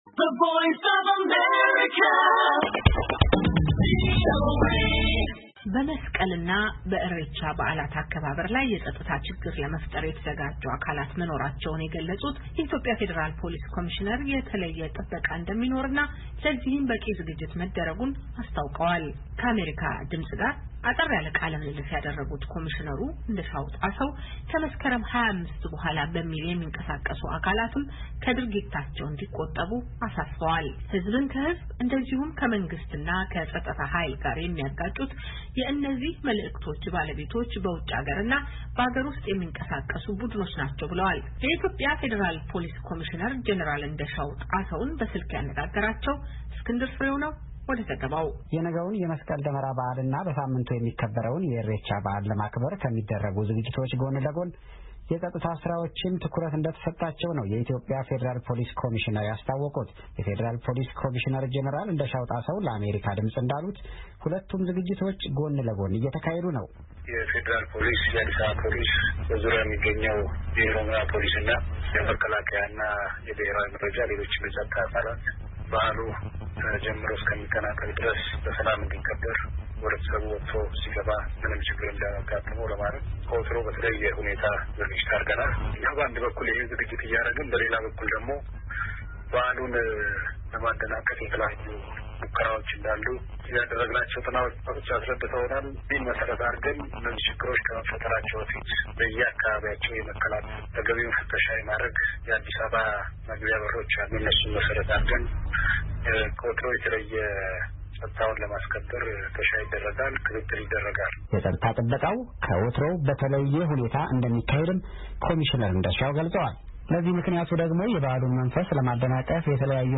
ከአሜሪካ ድምፅ ጋር አጠር ያለ ቃለምልልስ ያደረጉት ኮሚሽነሩ እንደሻው ጣሰው ከመስከረም 25/2013 ዓ.ም በኋላ በሚል የሚቀሰቅሱ አካላትም ከድርጊታቸው እንዲቆጠቡ አሳስበዋል::
ሕዝብን ክሕዝብ እንደዚሁም ከመንግሥትና ከፀጥታ ኃይል ጋር የሚያጋጩት የነዚህ መልዕክቶች ባለቤቶች በውጭ እና በሃገር ውስጥ የሚንቀሳቀሱ ቡድኖች ናቸው ብለዋል:: የኢትዮጵያ ፌደራል ፖሊስ ኮሚሽነር ጀነራል እንደሻው ጣሰውን በስልክ አነጋግረናቸዋል።